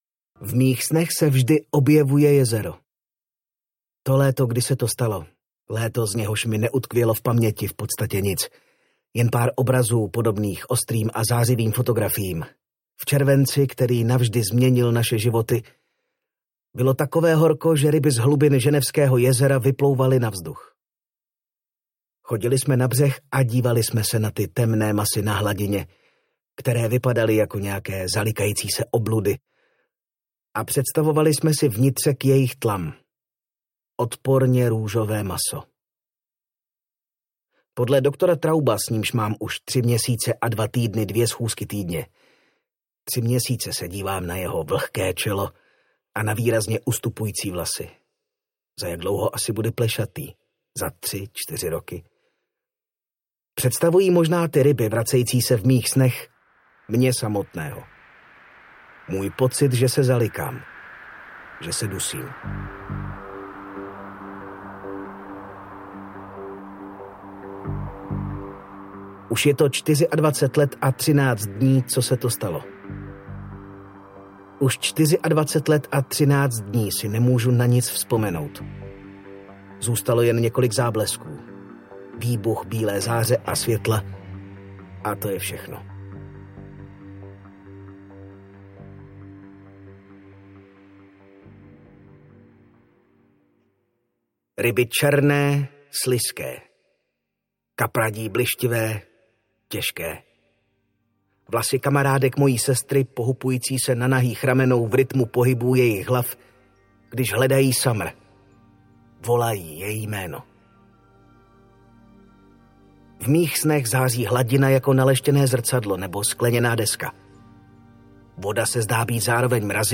Summer audiokniha
Ukázka z knihy